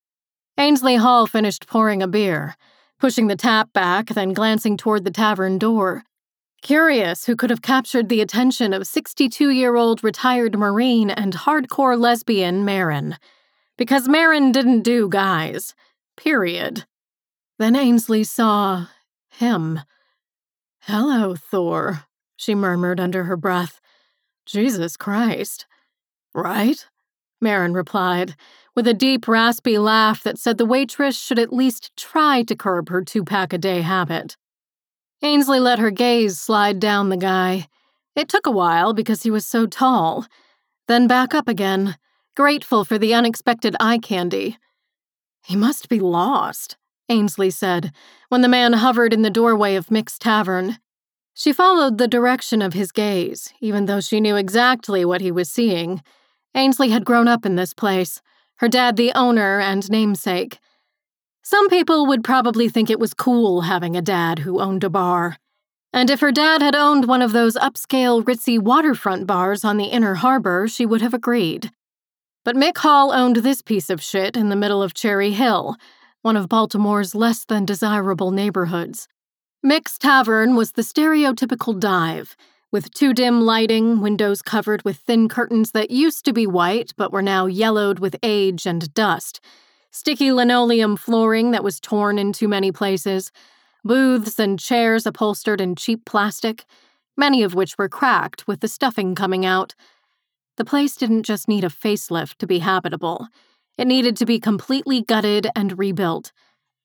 15_PD333_Resist_FemaleSample.mp3